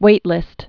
(wātlĭst)